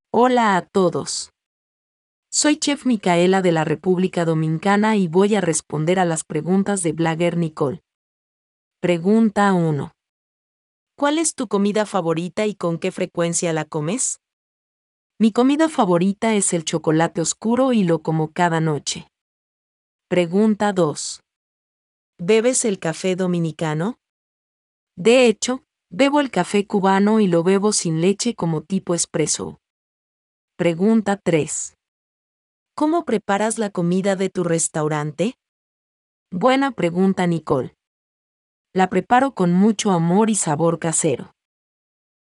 Listen as a food blogger asks a new and upcoming chef about their restaurant.